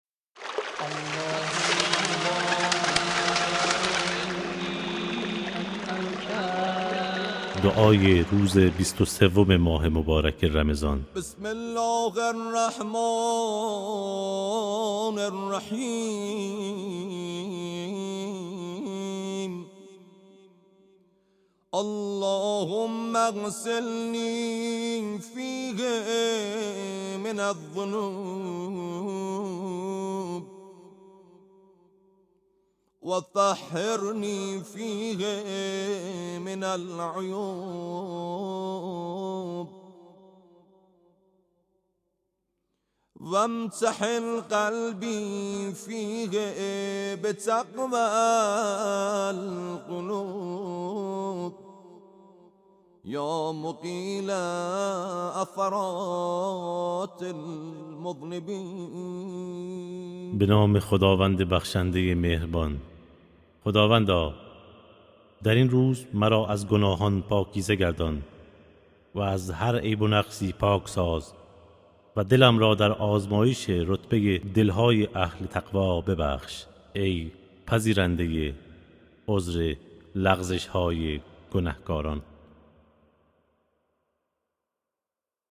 دعای روزهای ماه مبارک رمضان